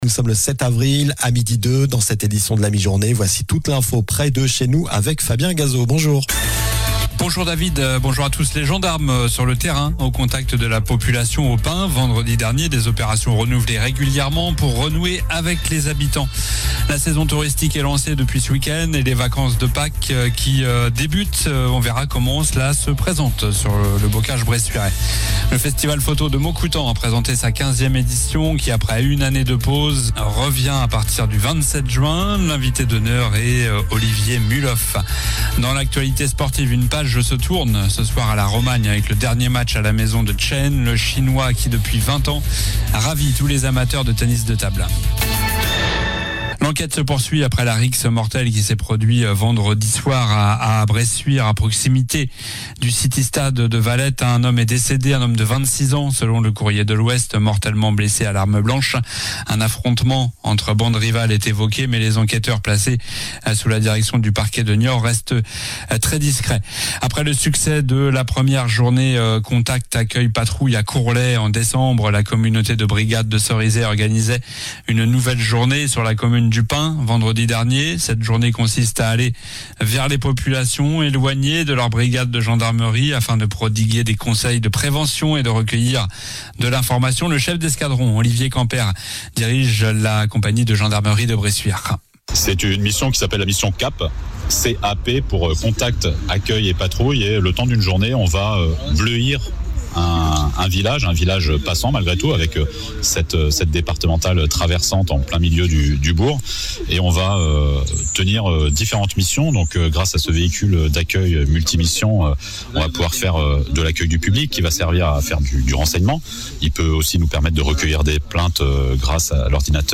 Journal du mardi 7 avril (midi)